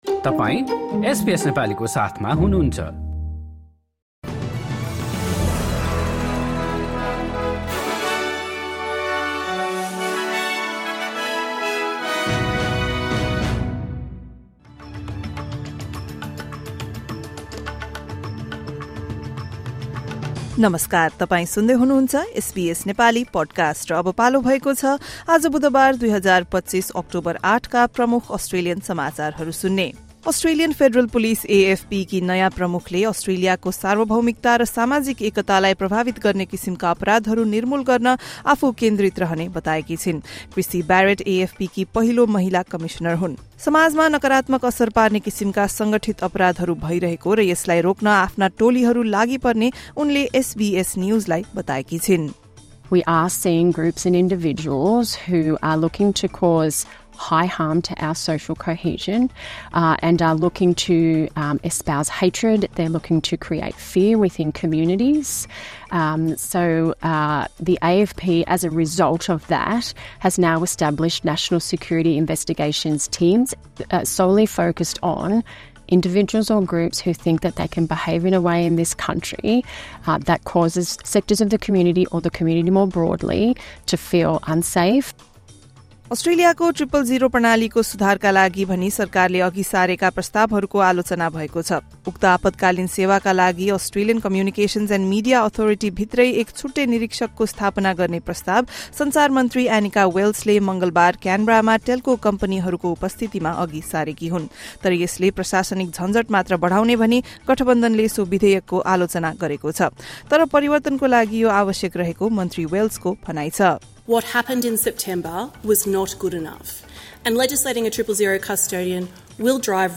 एसबीएस नेपाली प्रमुख अस्ट्रेलियन समाचार: बुधवार, ८ अक्टोबर २०२५